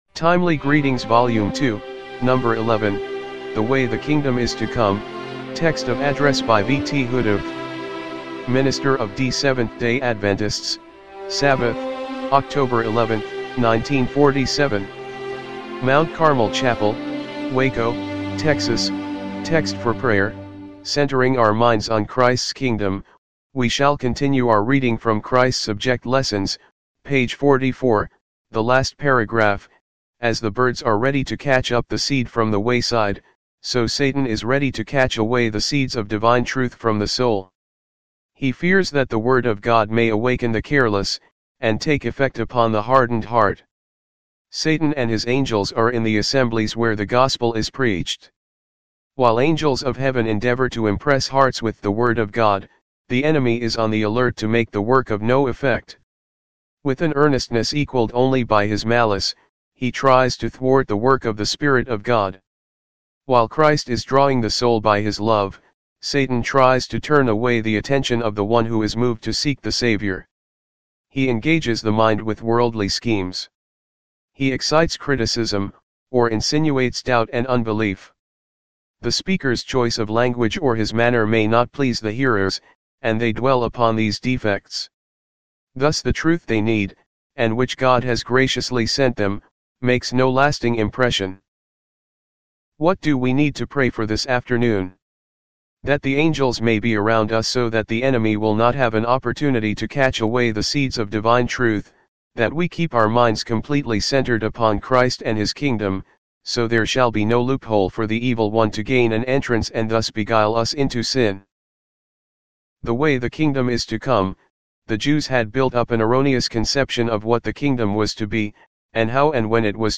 1947 MT. CARMEL CHAPEL WACO, TEXAS